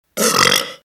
arroto-aerosmith.mp3